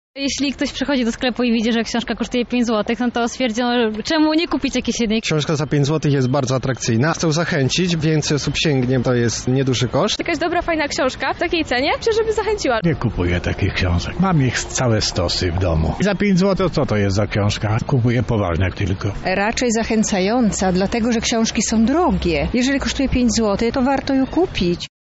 O tym czy warto kupić książkę w takiej cenie mówią klienci:
Sonda
Sonda.mp3